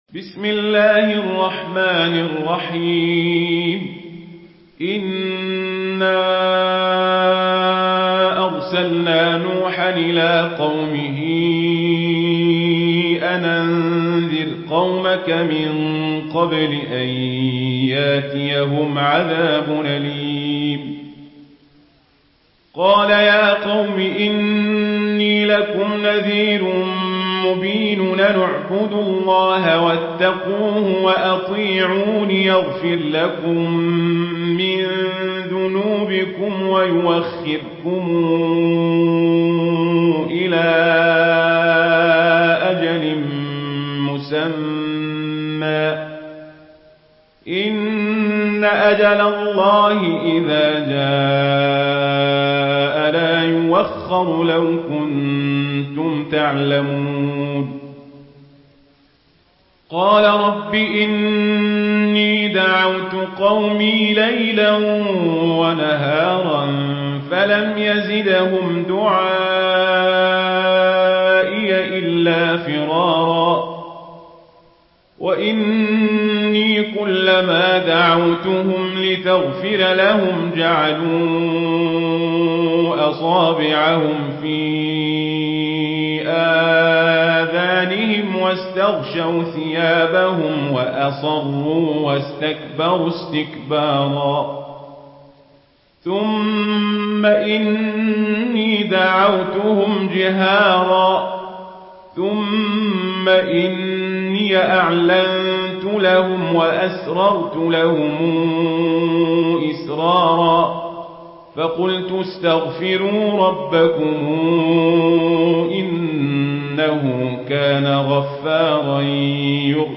Surah Nuh MP3 in the Voice of Omar Al Kazabri in Warsh Narration
Listen and download the full recitation in MP3 format via direct and fast links in multiple qualities to your mobile phone.